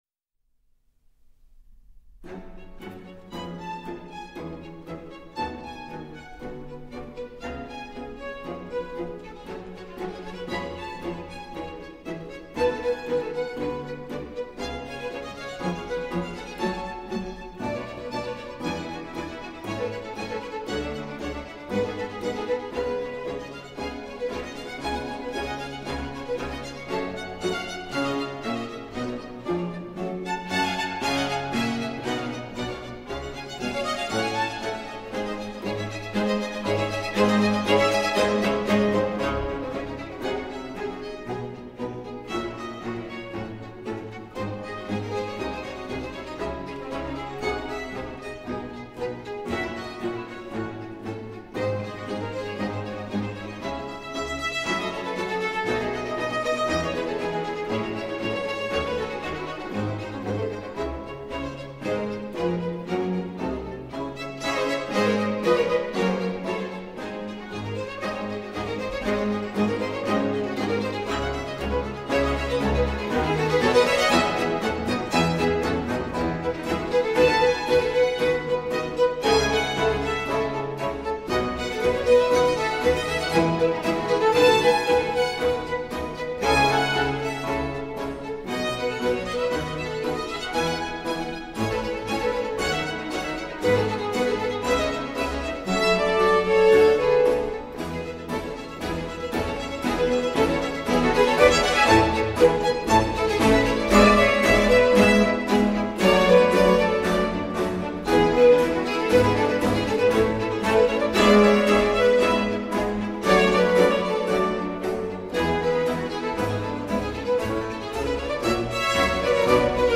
Concerto-Del-Vivaldi-RV-156-in-G-minor-Autograph-score-online-audio-converter.com_.mp3